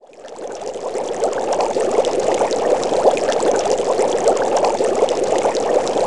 Listen to this monotonous sound of water bubbling.
water with a short fade-in. Notice how the water linearly fades in over the first two seconds, then remains at maximum volume throughout.
water_shortfade.wav